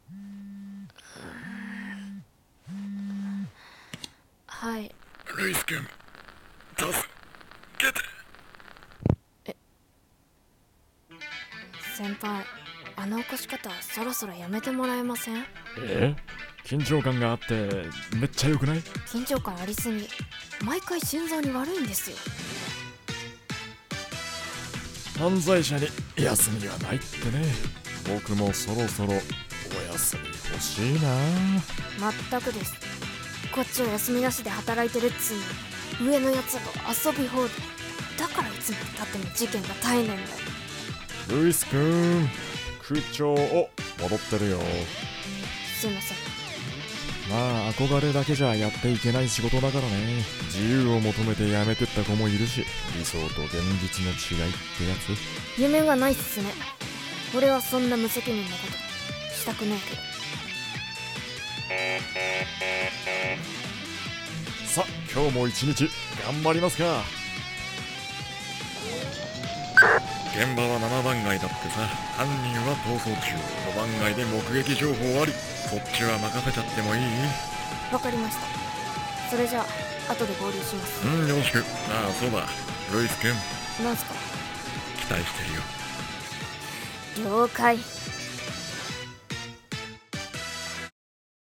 【声劇台本】Troublemaker